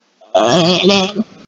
Play, download and share strangest noise on the planet original sound button!!!!
strangest-noise-on-the-planet.mp3